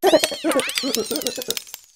gholdengo_ambient.ogg